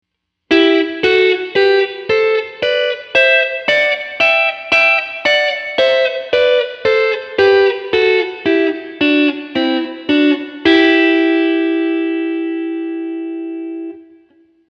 まずは1弦と2弦による3度ダブルストップのパターンです。
3度音程のダブルストップ1弦、2弦